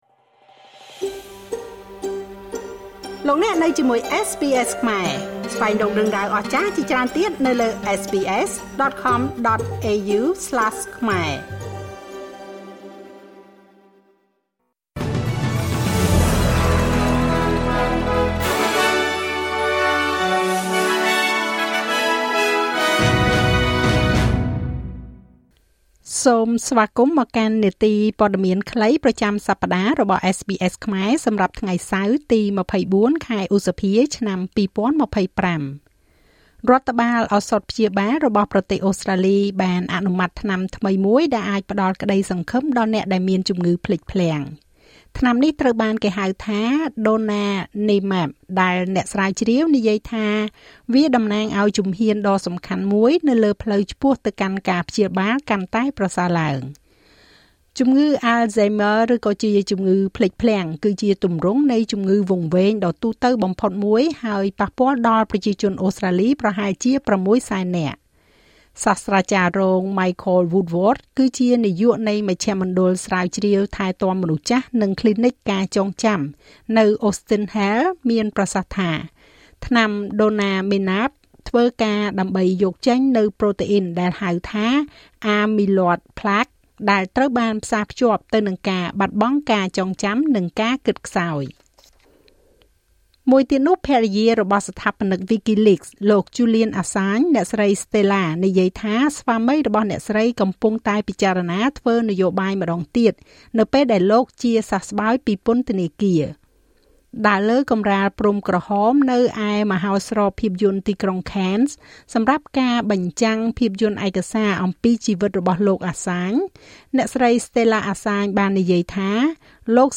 នាទីព័ត៌មានខ្លីប្រចាំសប្តាហ៍របស់SBSខ្មែរ សម្រាប់ថ្ងៃសៅរ៍ ទី២៤ ខែឧសភា ឆ្នាំ២០២៥